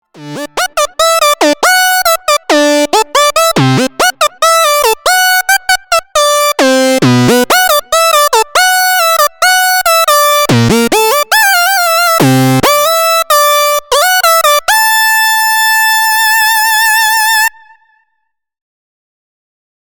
Mini Lead 1
軽快かつ太さのあるリードは